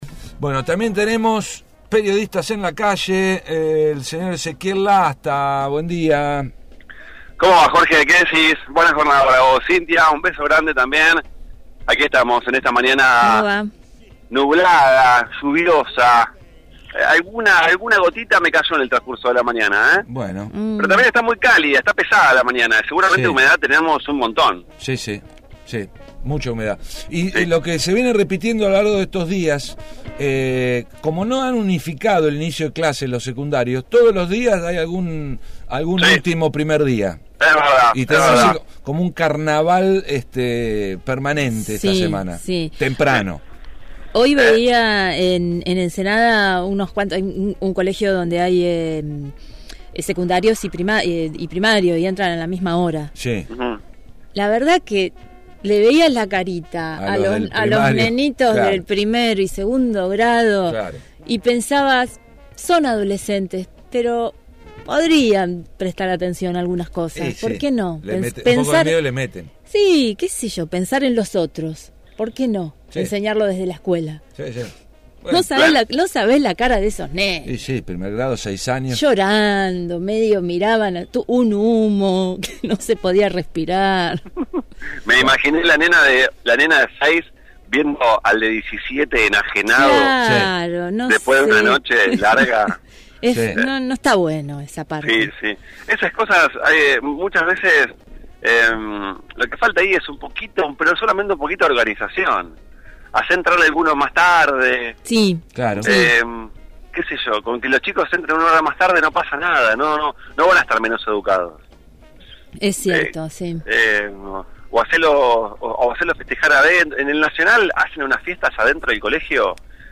MÓVIL/ Marcha en repudio a la represión a trabajadores de SOEME